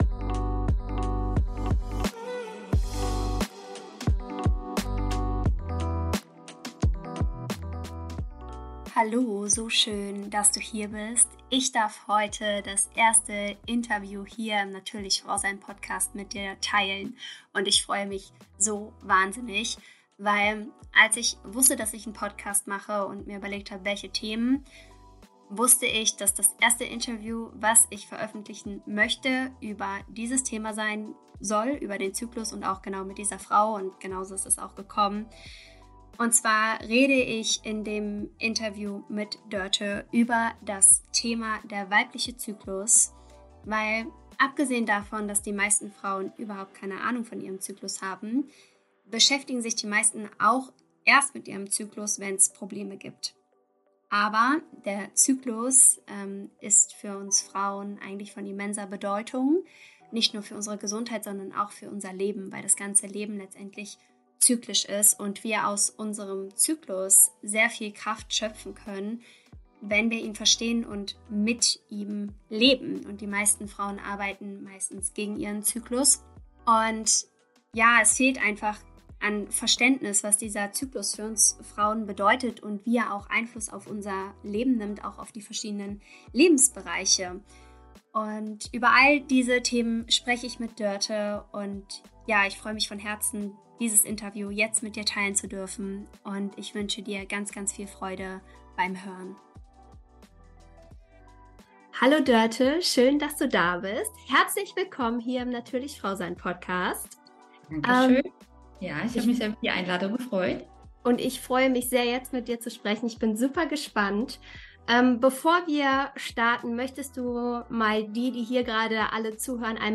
Ich freue mich so sehr, dieses Interview jetzt mit dir teilen zu können. Es geht um die tiefer liegende Bedeutung des weiblichen Zyklus für uns Frauen.